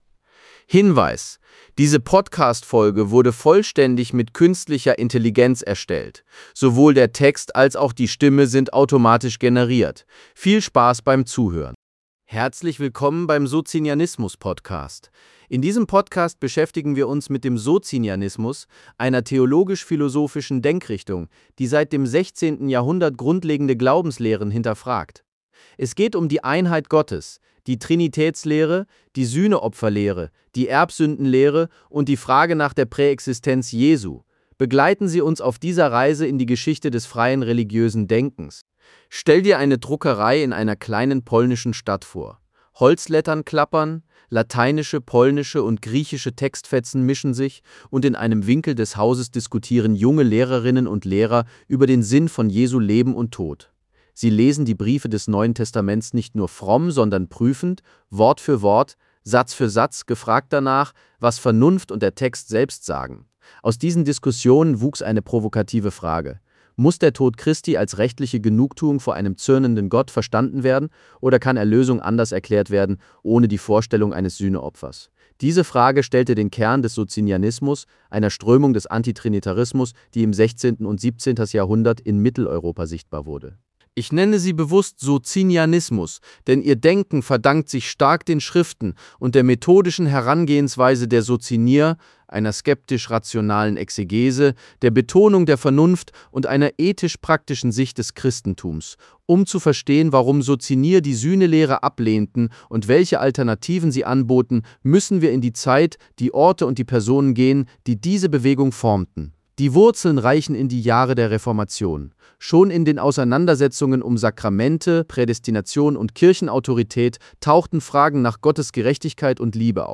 Diese Folge führt in die historische Entstehung des Socinianismus und zeigt, wie eine Gemeinschaft der Reformation das Verständnis von Erlösung radikal neu gedachte: vernunftgeleitete Bibelauslegung, Ablehnung der Satisfaktionslehre und ein Fokus auf Nachfolge und moralische Erneuerung. Erzählerisch und sachlich – ein Einstieg in die Hintergründe, die Persönlichkeiten und Institutionen, die diese Sicht prägten.